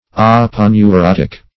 \Ap`o*neu*rot"ic\